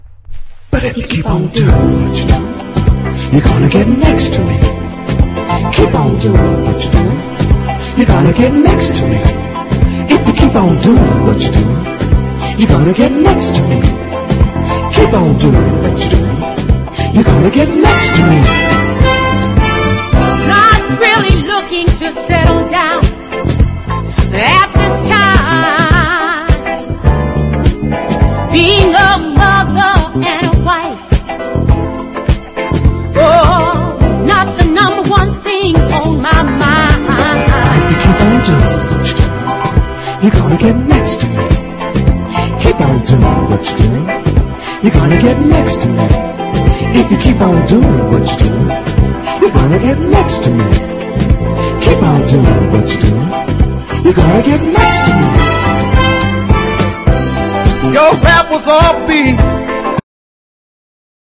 SOUL / SOUL / FREE SOUL / KIDS SOUL